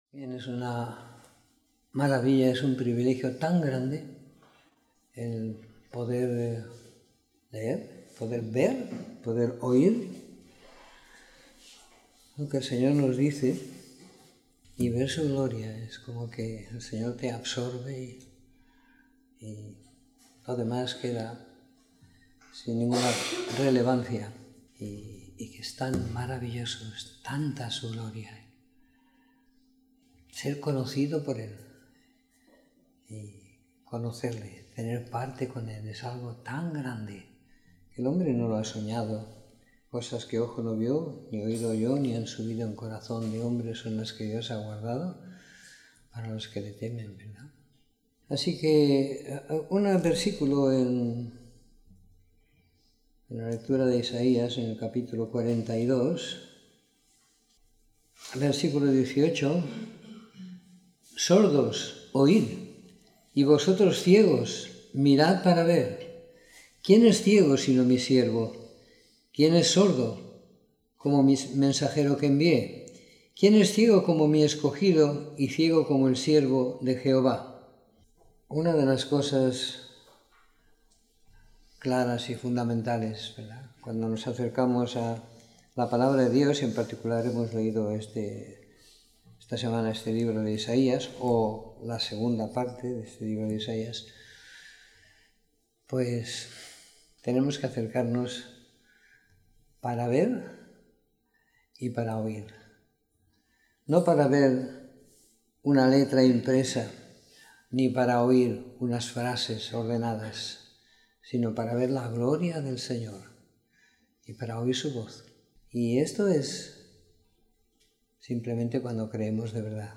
Comentario en los libros de Isaías del capítulo 36 al 66 y Salmos del capítulo 145 al 150 siguiendo la lectura programada para cada semana del año que tenemos en la congregación en Sant Pere de Ribes.